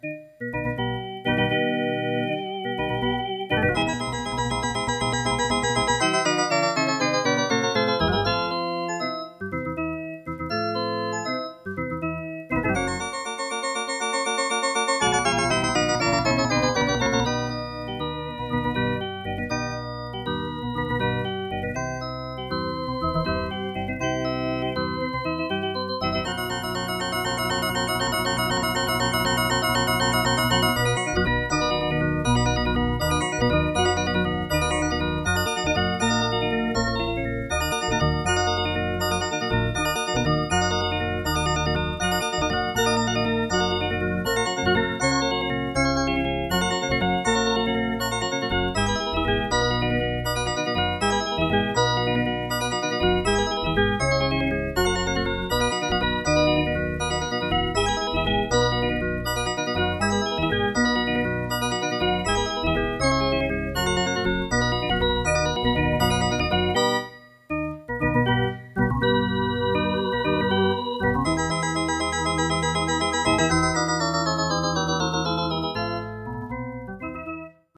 'Walkyrie'  - Clip made using a Jazz Organ preset